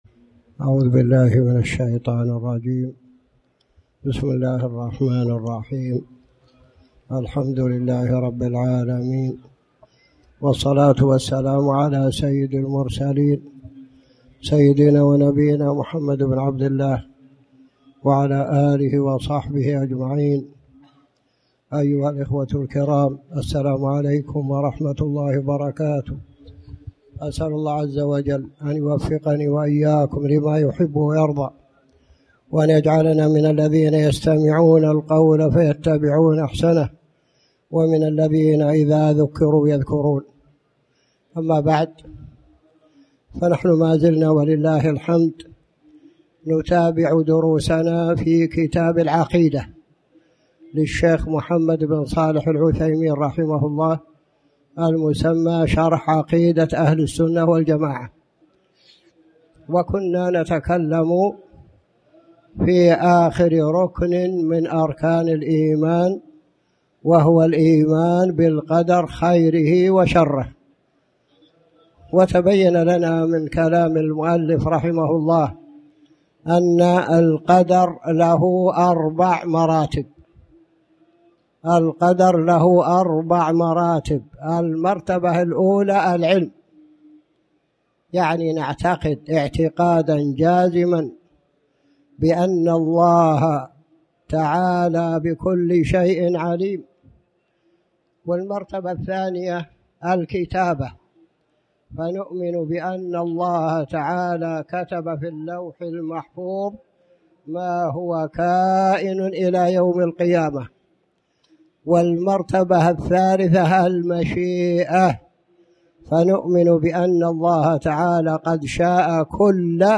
تاريخ النشر ٧ ربيع الأول ١٤٣٩ هـ المكان: المسجد الحرام الشيخ